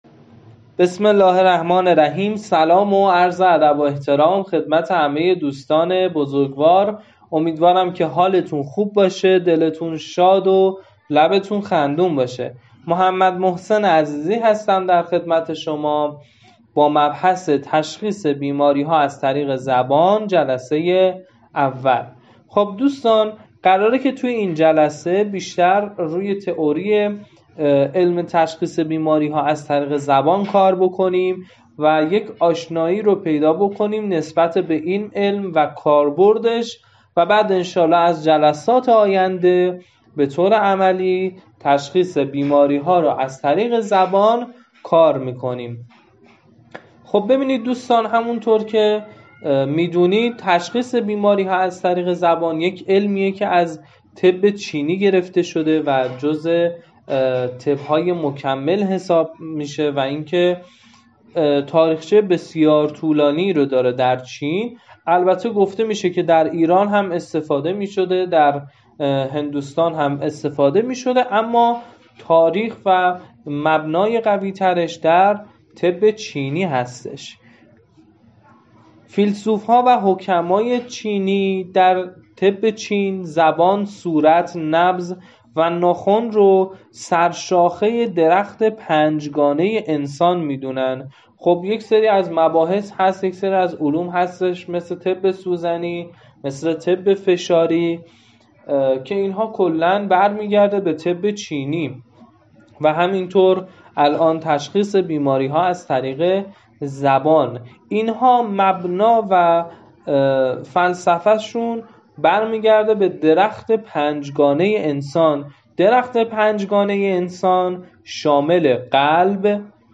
- 53 دقیقه صوت آموزشی - جزوه کامل 103صفحه ای PDF- تصاویر رنگی و آموزشی